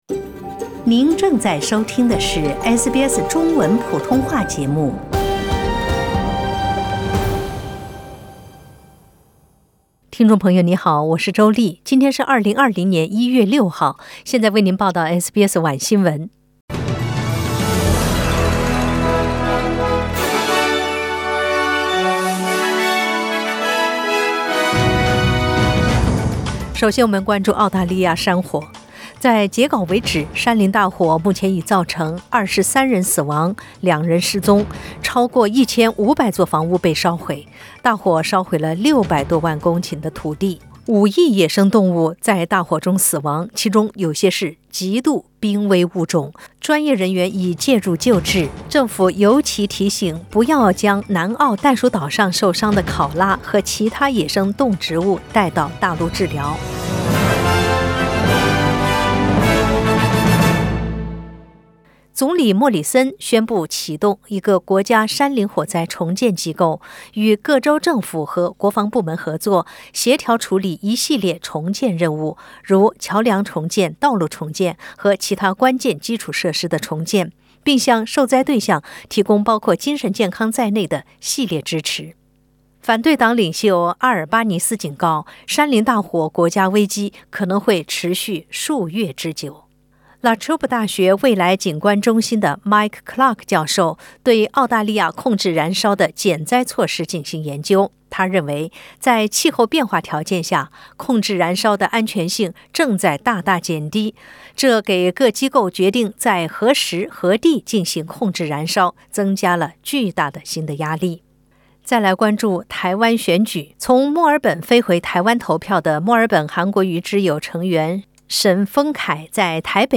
SBS 晚新闻 （1月6日）